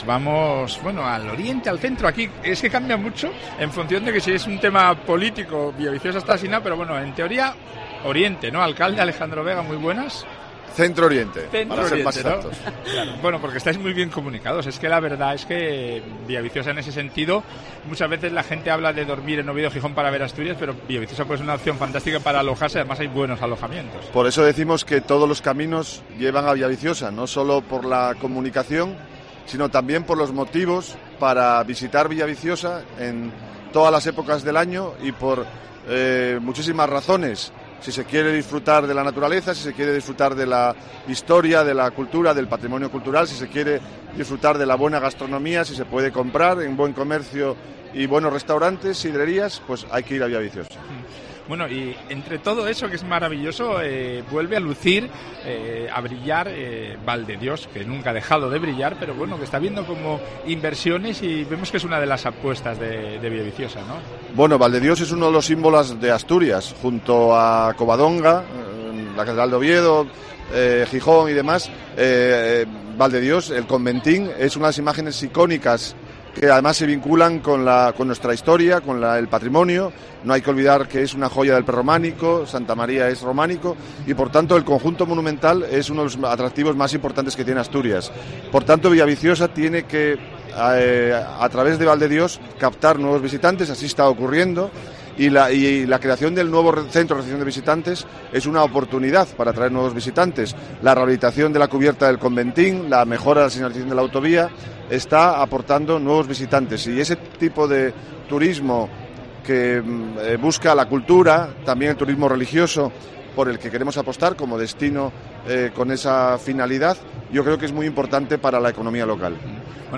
Entrevista con el alcalde de Villaviciosa, Alejandro vega